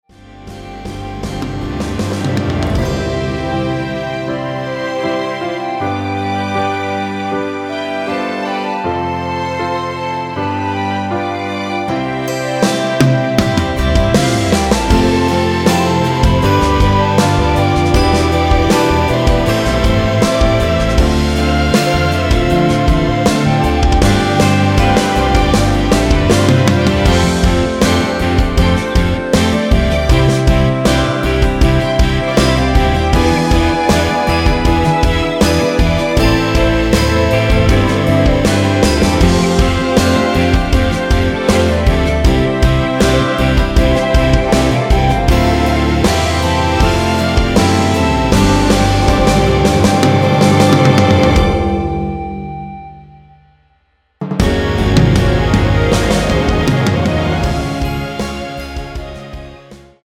◈ 곡명 옆 (-1)은 반음 내림, (+1)은 반음 올림 입니다.
노래방에서 노래를 부르실때 노래 부분에 가이드 멜로디가 따라 나와서
앞부분30초, 뒷부분30초씩 편집해서 올려 드리고 있습니다.
중간에 음이 끈어지고 다시 나오는 이유는
축가 MR